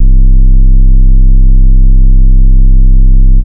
DIST4M808.wav